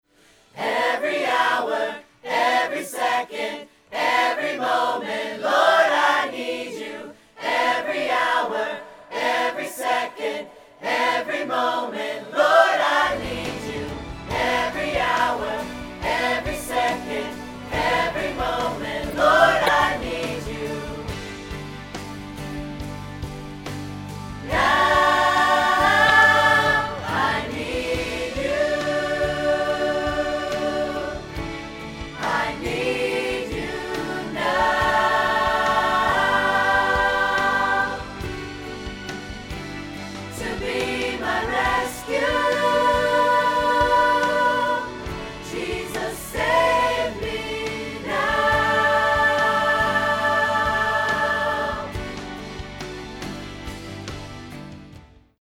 • 0:00 – 0:06 – Choir Mics Soloed
• 0:07 – 0:13 – Choir Mics with Reverb
• 0:13 – 0:55 – Choir Mics with Backing Track
Audix MicroBoom MB5050